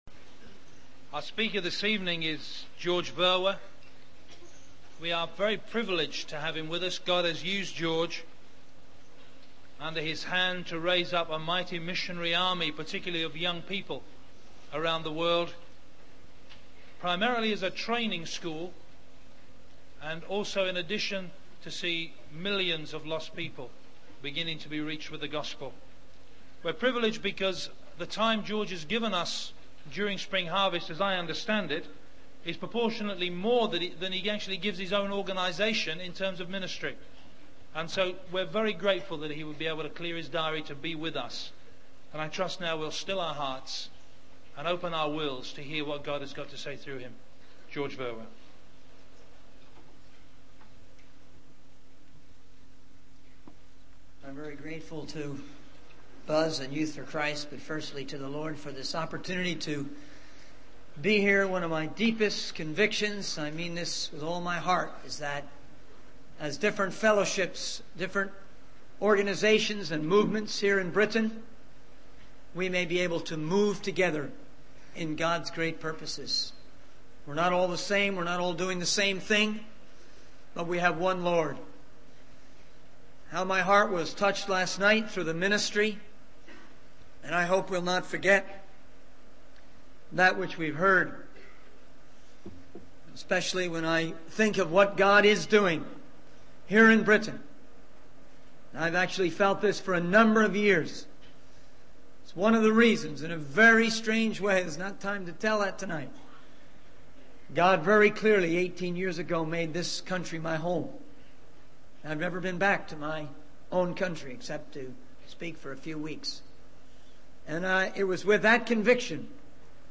In this sermon, the preacher emphasizes the importance of being committed to God's race.